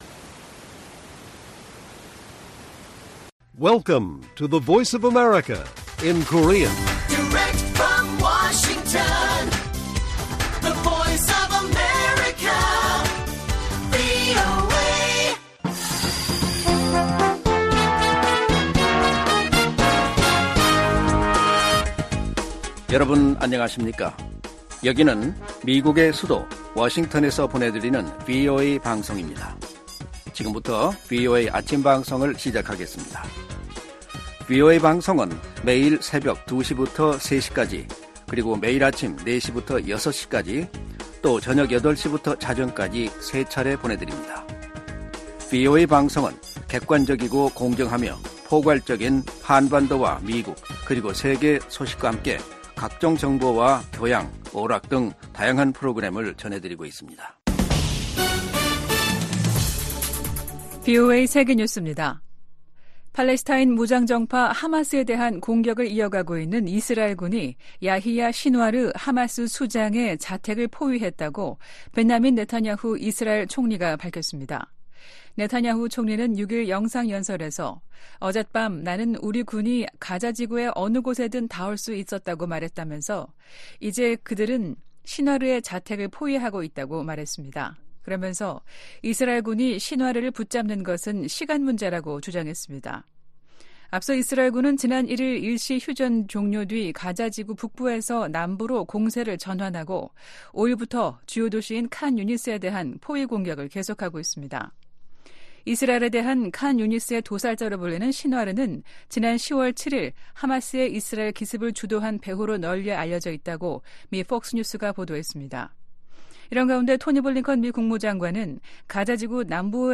세계 뉴스와 함께 미국의 모든 것을 소개하는 '생방송 여기는 워싱턴입니다', 2023년 12월 8일 아침 방송입니다. '지구촌 오늘'에서는 안토니우 구테흐스 유엔 사무총장이 가자지구 내 임박한 인도적 재앙을 경고하며 유엔헌장 99조를 발동한 소식 전해드리고, '아메리카 나우'에서는 우크라이나 지원 등이 포함된 1천 110억 달러 규모 지원 예산안이 상원에서 절차 투표 통과에 실패한 이야기 살펴보겠습니다.